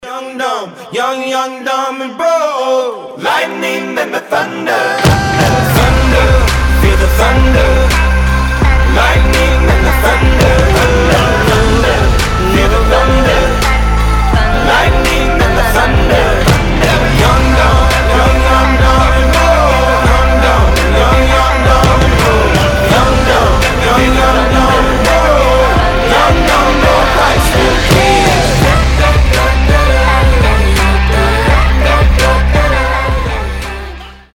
• Качество: 320, Stereo
ритмичные
мужской вокал
dance
Electronic
RnB
mash up
Reggaeton